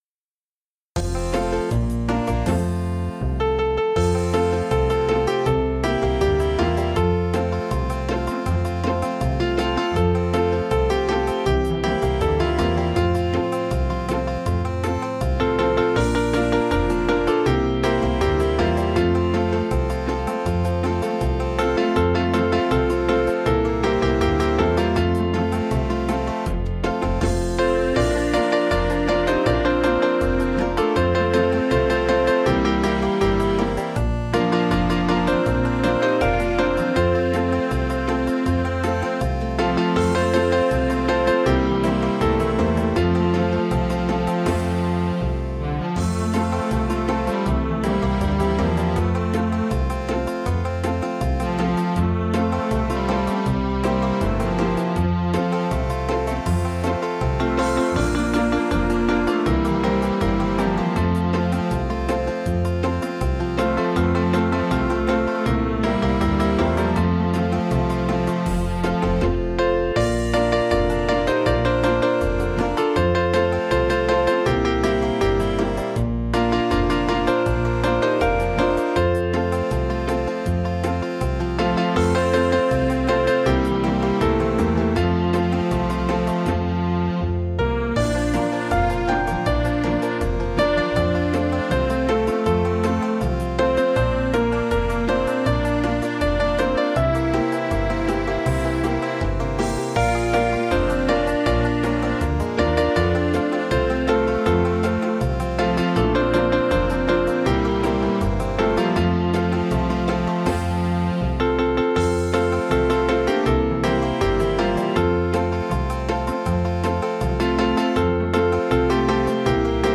8-beat intro.
Play parts: Verse, Chorus, Verse, Chorus, Bridge, Verse, Chorus.
Listen to the AI Performers play "Grandpa's Banjo" (mp3)
grandpas_banjo1_conv.mp3